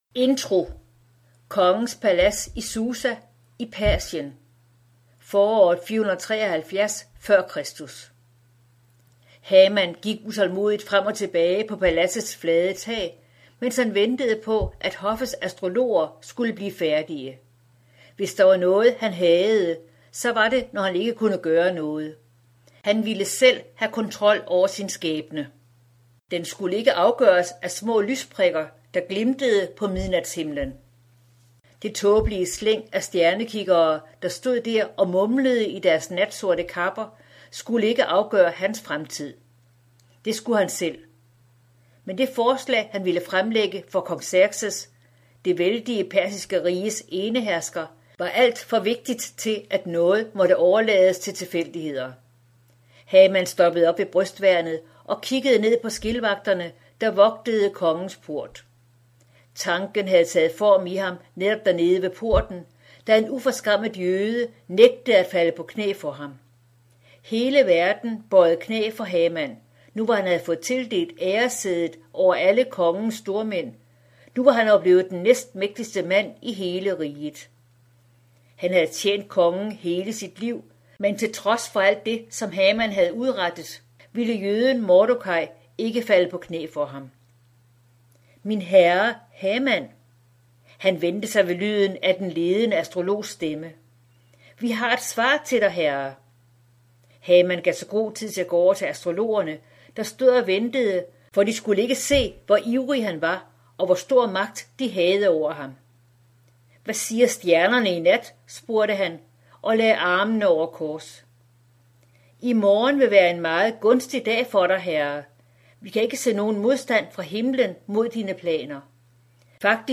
Hør et uddrag af Pagtens vogtere Pagtens vogtere Format MP3 Forfatter Lynn Austin Bog Lydbog E-bog 249,95 kr.